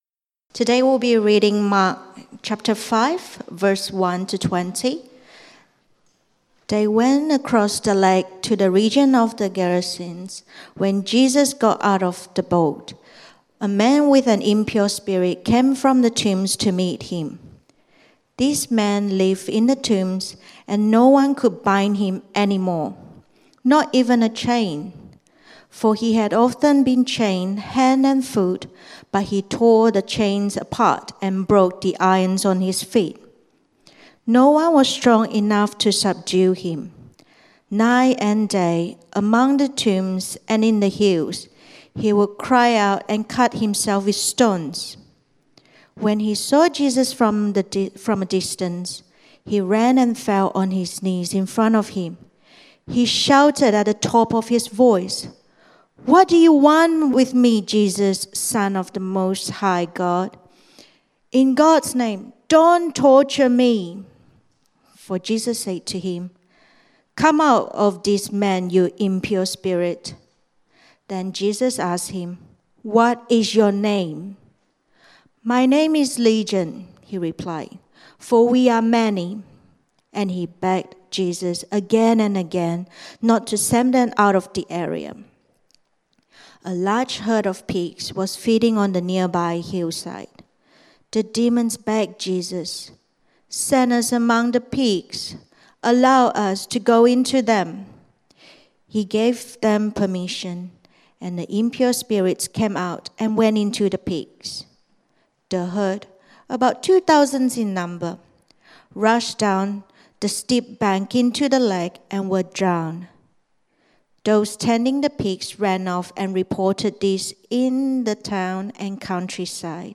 Meet Jesus Sermon outline